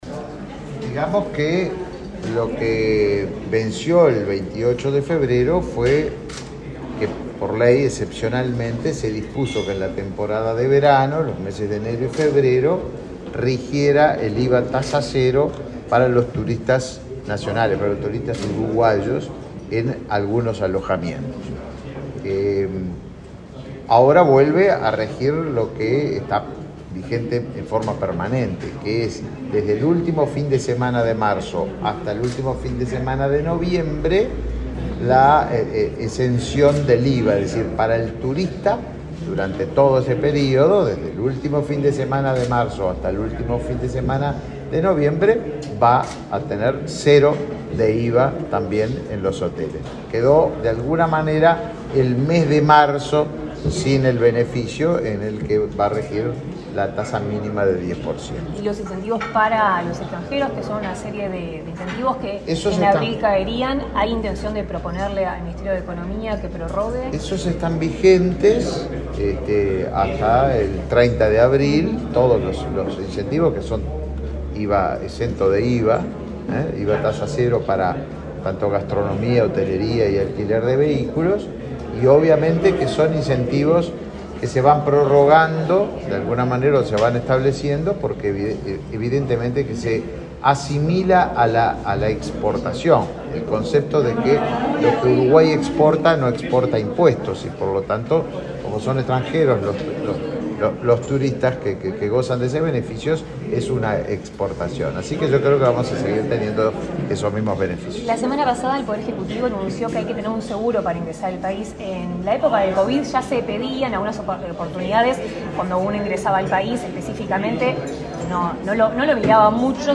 Declaraciones del ministro de Turismo, Tabaré Viera
El ministro Tabaré Viera participó en el evento y luego dialogó con la prensa.